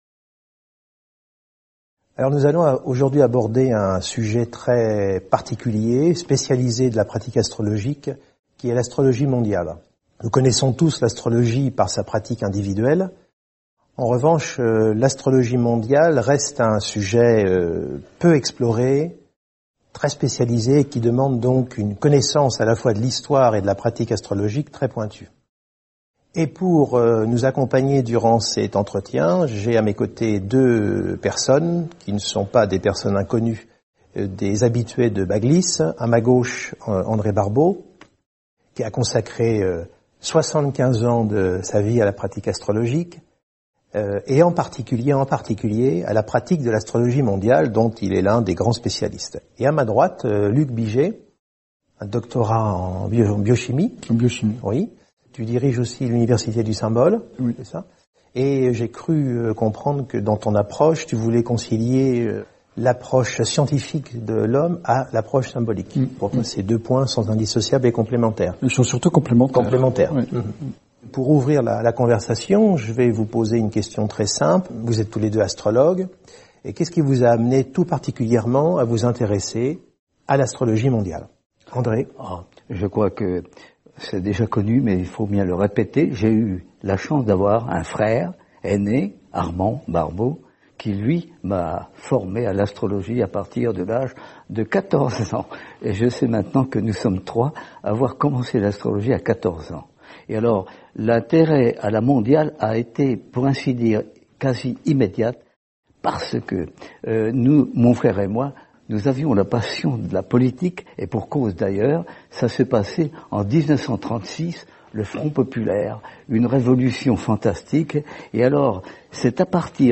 En date du 18 janvier 2011, j’avais organisé et animé une rencontre portant sur le thème : « Astrologie mondiale : Les grands cycles de l’humanité.